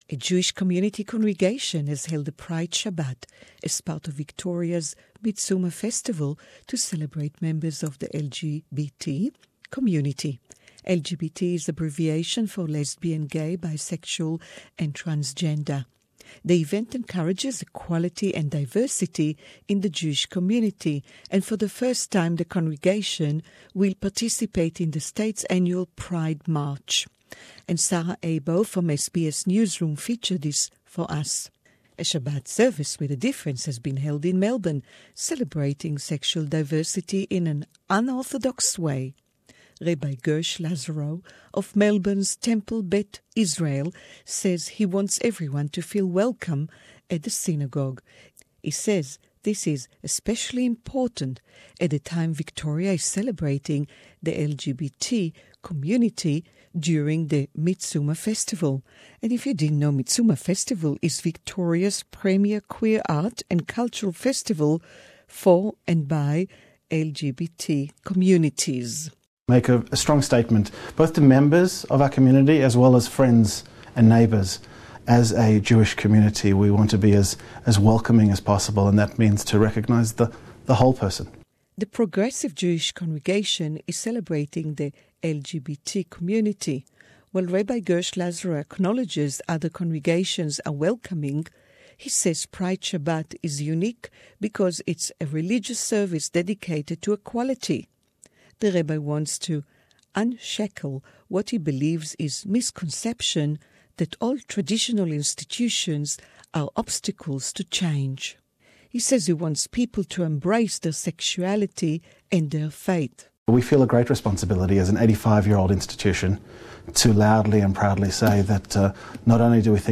"Pride" Shabbat celebrated in Melbourne Feature (English)